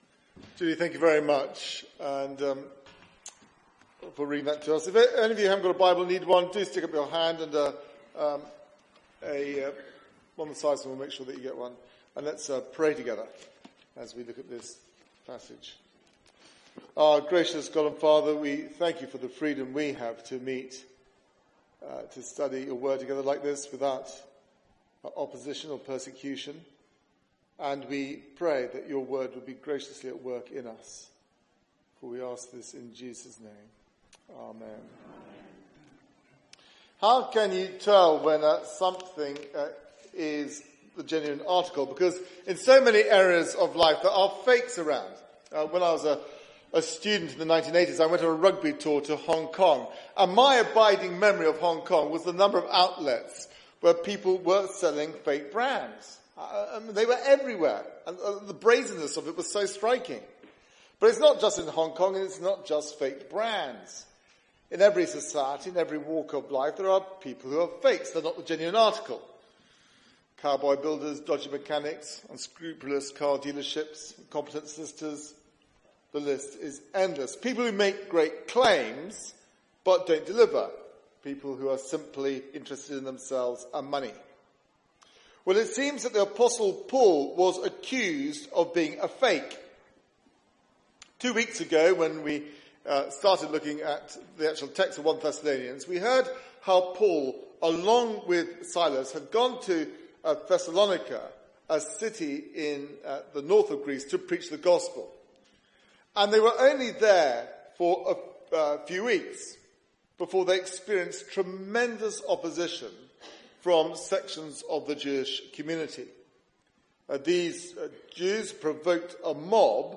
Media for 9:15am Service on Sun 28th May 2017 09:15 Speaker
Theme: Real Christian Ministry Sermon